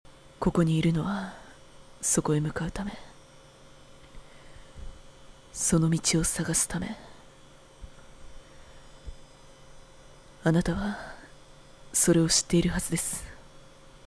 ■　Voice　■